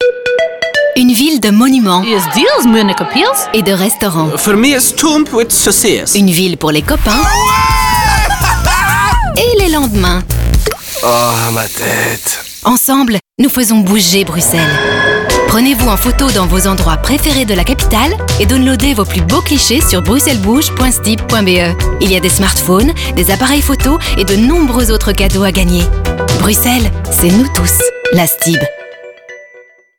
Radio: Sonicville
Spot radio FR 2